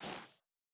Bare Step Snow Medium B.wav